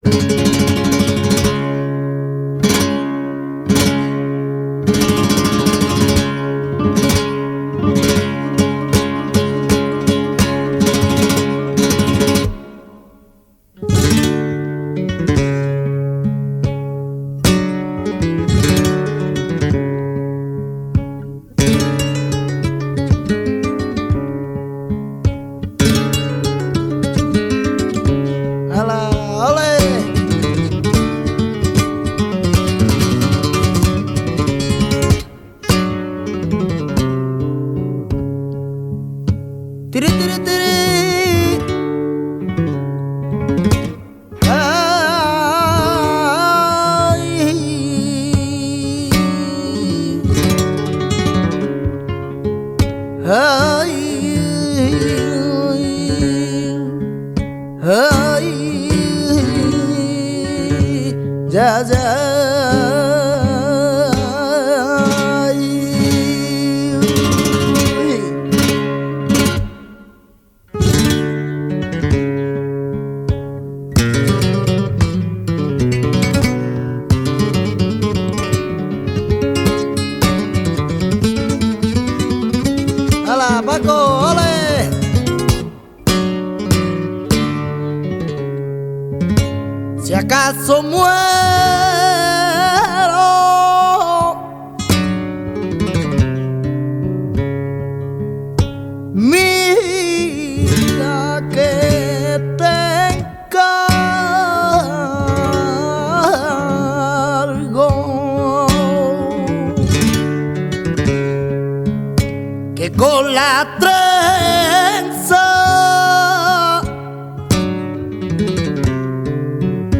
Siguiriyas